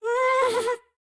Minecraft Version Minecraft Version snapshot Latest Release | Latest Snapshot snapshot / assets / minecraft / sounds / mob / happy_ghast / ambient11.ogg Compare With Compare With Latest Release | Latest Snapshot